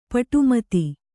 ♪ paṭu mati